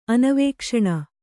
♪ anavēkṣaṇa